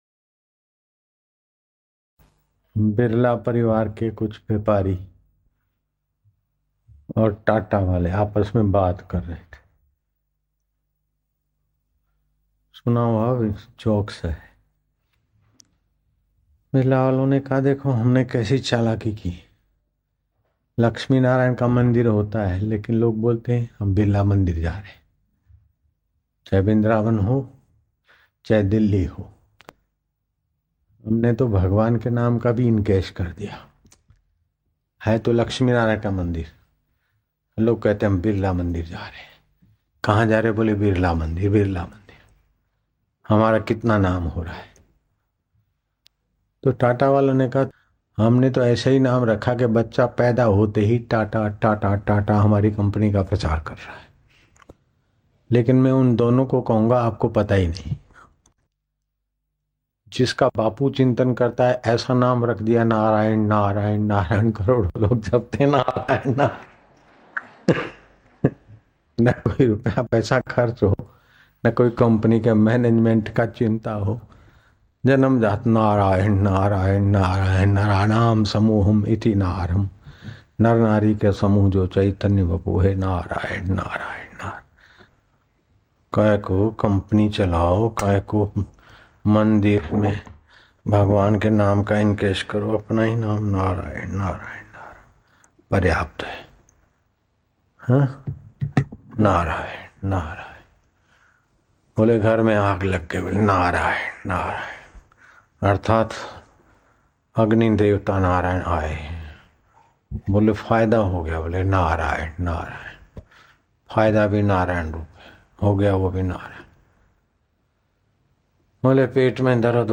Audio Satsang MP3s of Param Pujya Sant Shri Asharamji Bapu Ashram